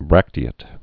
(brăktē-ĭt, -āt)